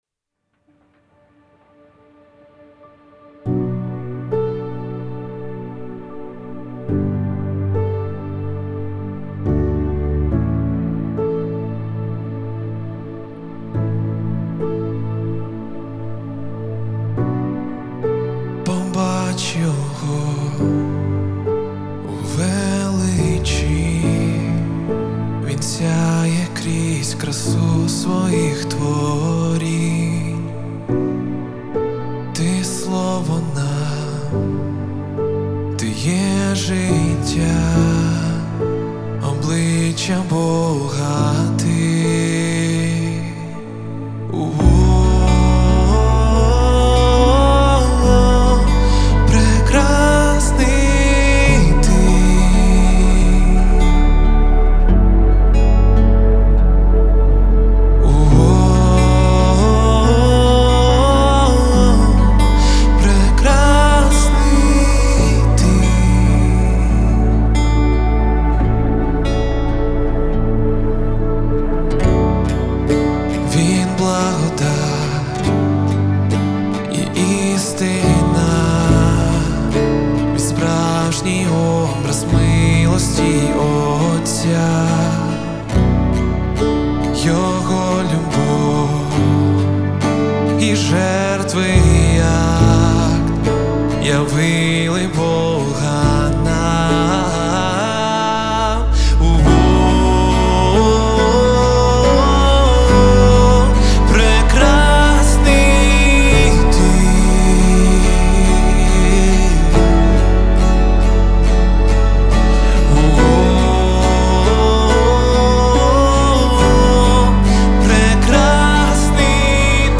231 просмотр 258 прослушиваний 34 скачивания BPM: 70